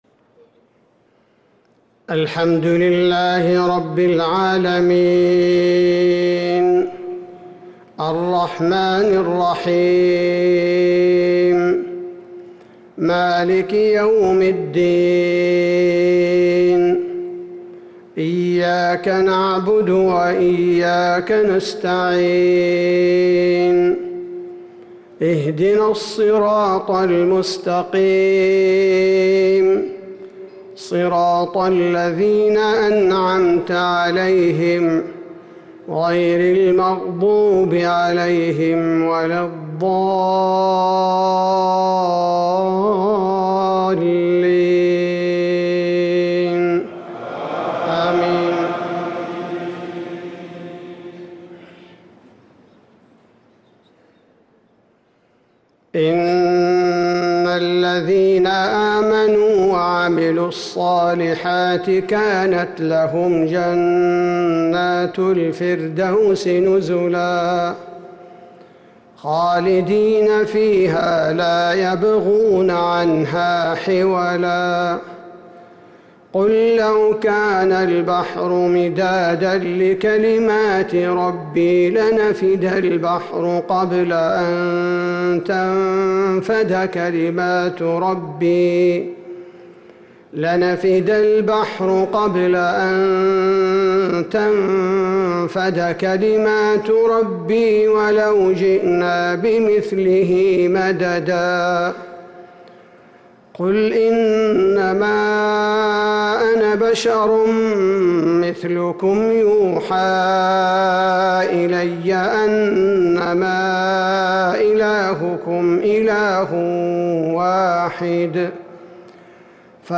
صلاة المغرب للقارئ عبدالباري الثبيتي 17 ذو القعدة 1444 هـ
تِلَاوَات الْحَرَمَيْن .